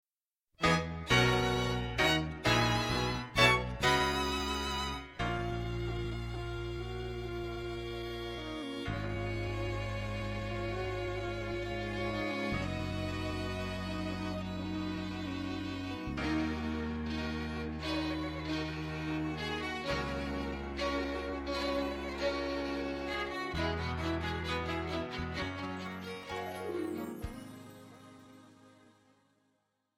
Pop , R&B
BV Yes